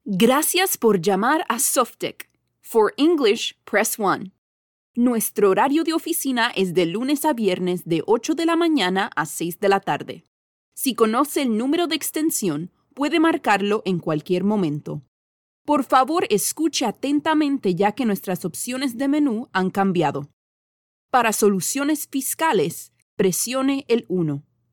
Ma voix combine naturellement chaleur, clarté et énergie dynamique, ce qui en fait un choix parfait pour les projets qui nécessitent un son relatable et professionnel.